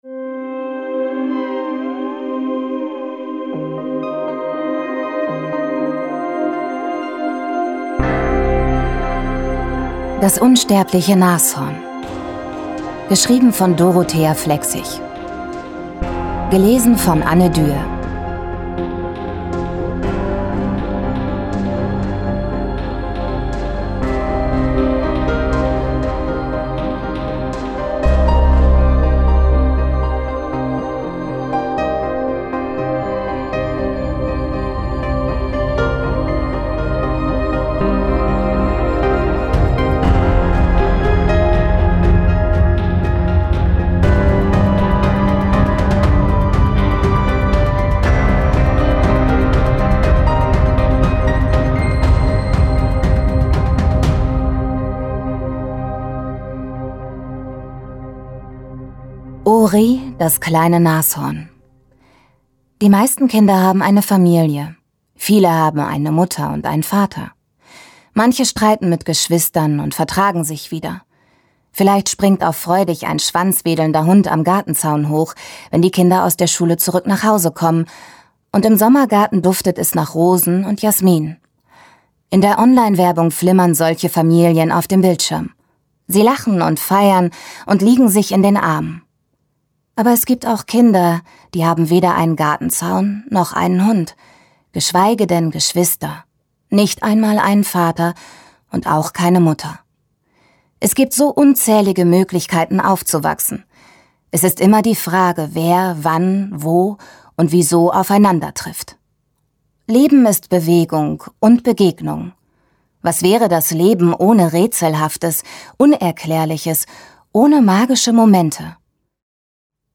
Das unsterbliche Nashorn Audio CD
Eine magische Geschichte über Wunscherfüllung und wunderbare Wendungen. Spannendes Kinderhörbuch über Freundschaft und Familie, Verlust und Trost.